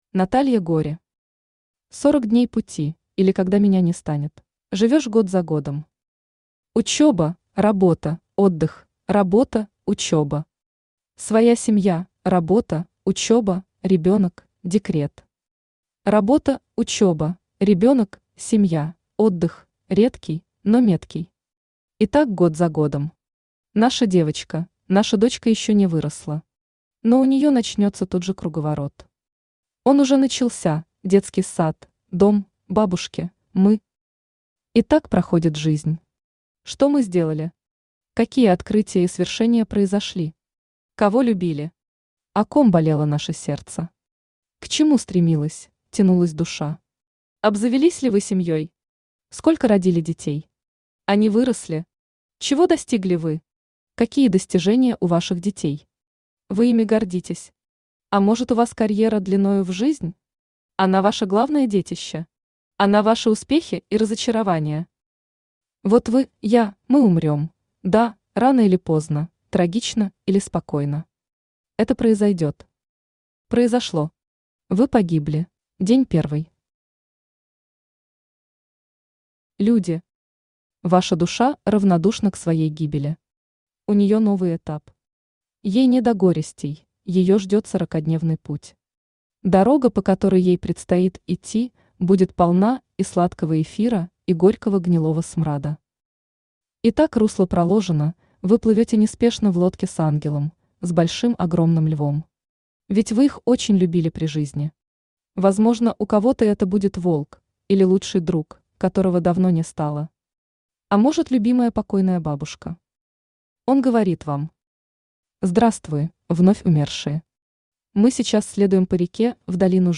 Аудиокнига 40 дней пути, или Когда меня не станет | Библиотека аудиокниг
Aудиокнига 40 дней пути, или Когда меня не станет Автор Наталья Юрьевна Гори Читает аудиокнигу Авточтец ЛитРес.